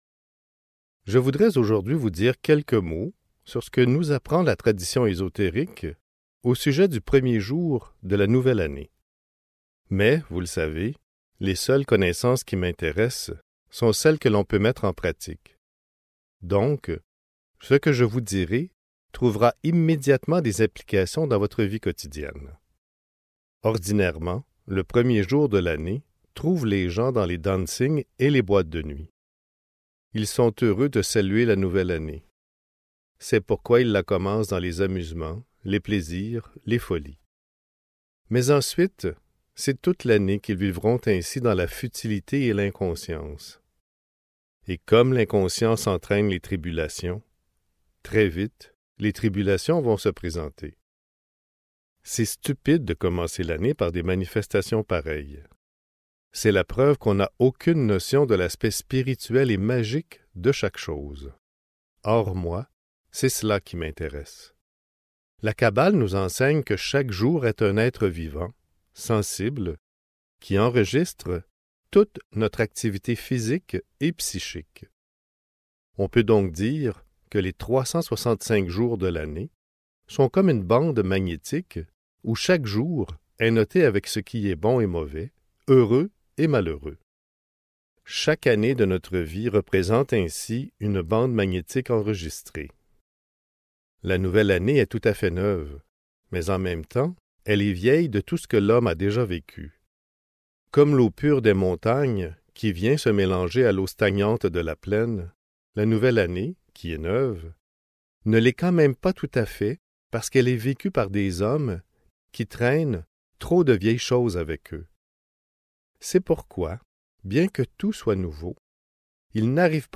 L'année nouvelle (Livre audio | CD MP3) | Omraam Mikhaël Aïvanhov
Des livres lus, des livres à écouter !